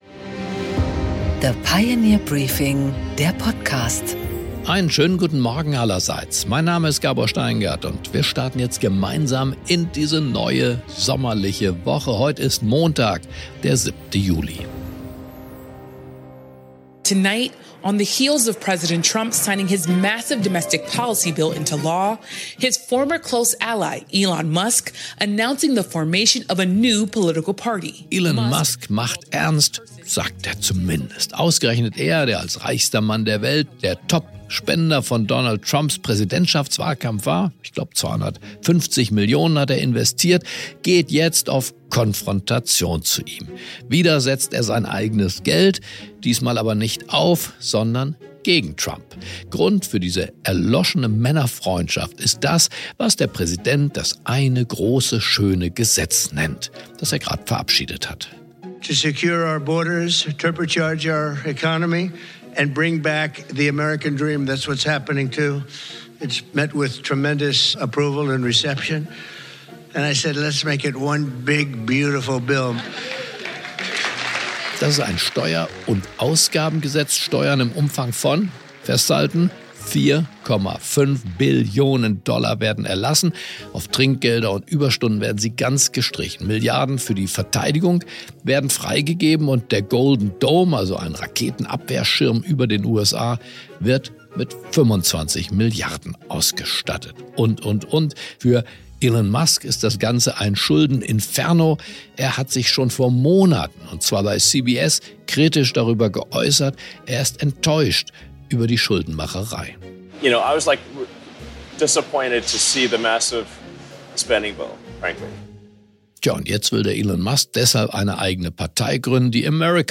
Gabor Steingart präsentiert das Pioneer Briefing
Im Interview: Thomas Geisel, BSW-Europa-Abgeordneter und Energieexperte, spricht mit Gabor Steingart über den Ukraine-USA-Rohstoff-Deal und warum er diesen sehr kritisch sieht.